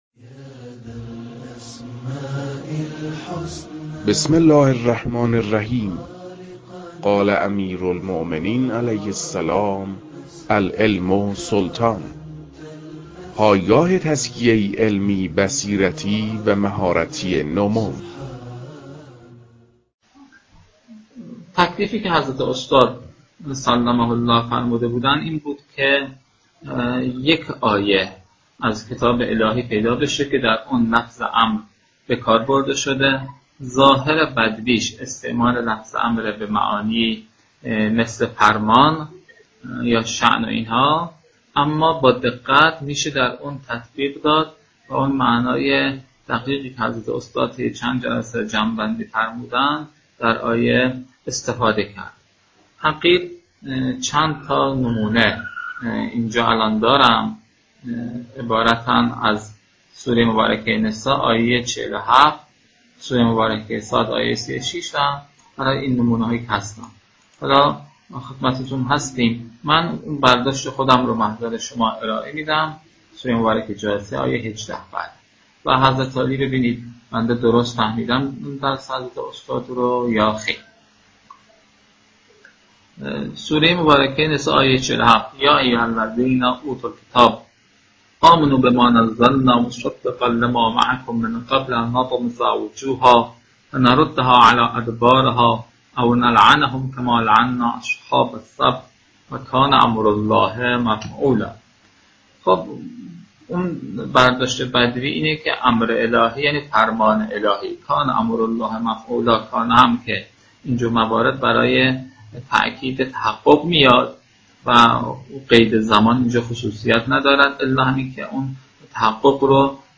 مباحثه